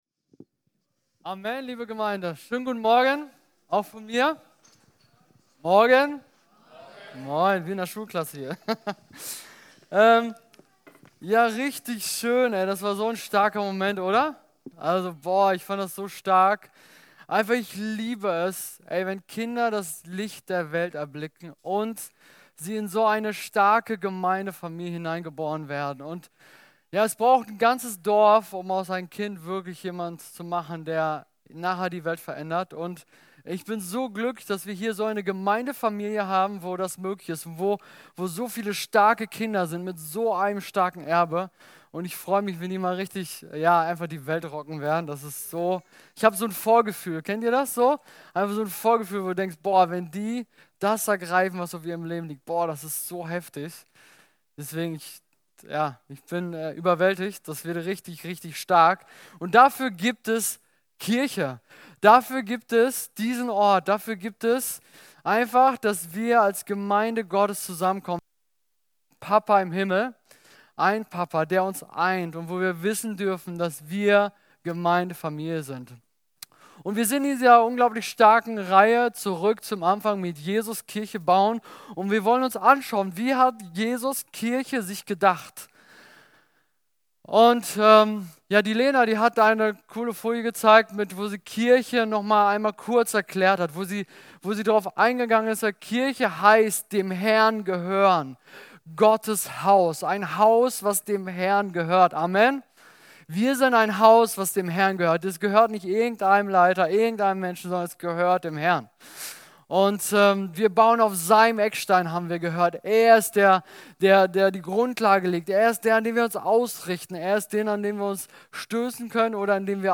Podcast unserer Predigten